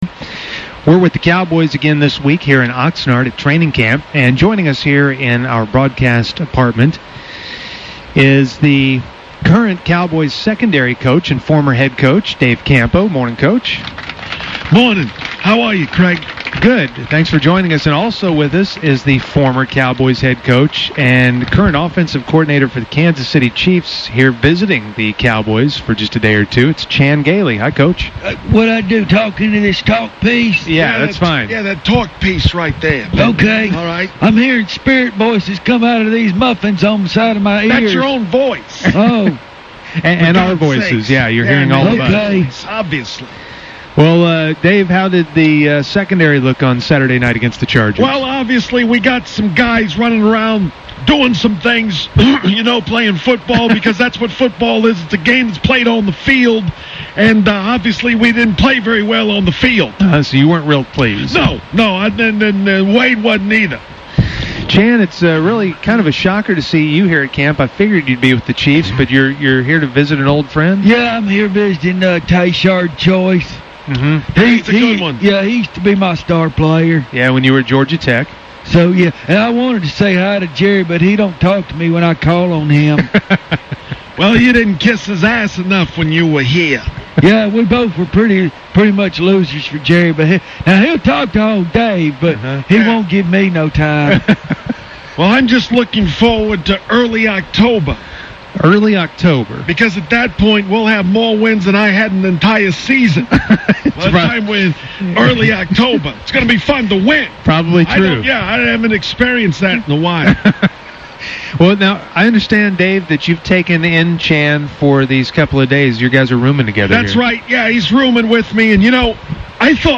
In this audio, the fake Chan Gailey and fake Dave Campo talk about the recent Cowboy pre-season game and Gailey fills us in on a new body part he is sporting these days.